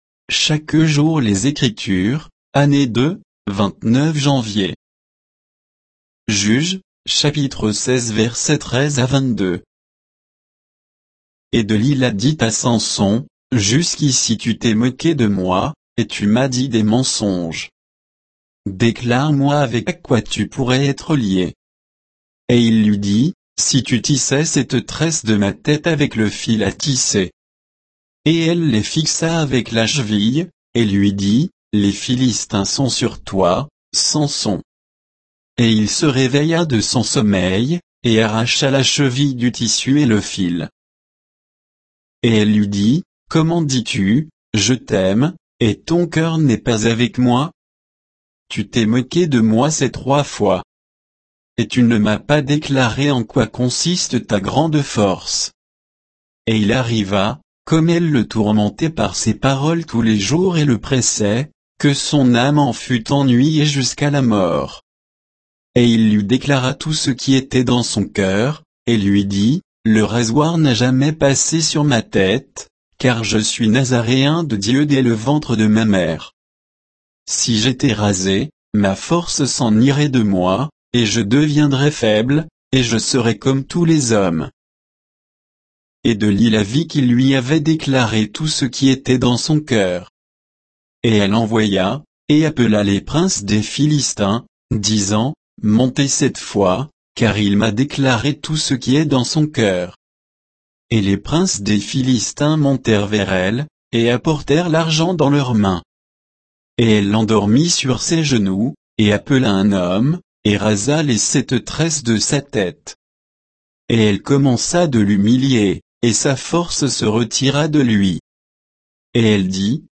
Méditation quoditienne de Chaque jour les Écritures sur Juges 16, 13 à 22